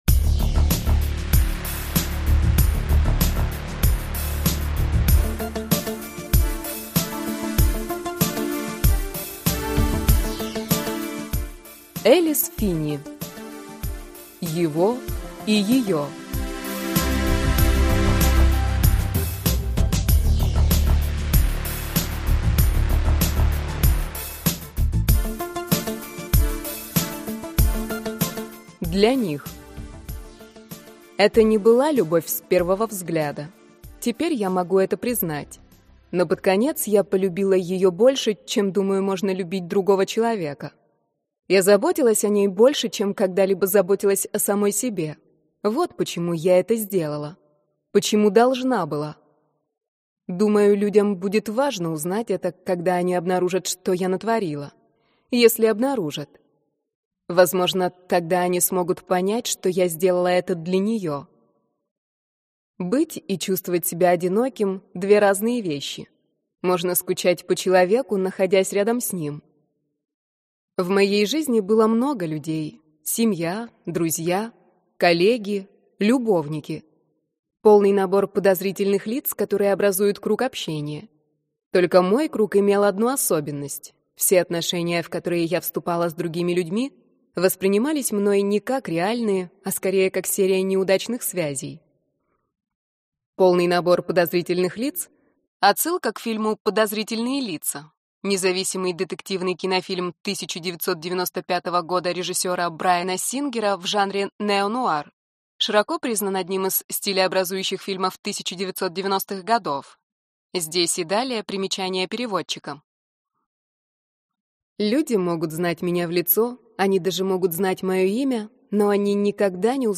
Аудиокнига Его и ее | Библиотека аудиокниг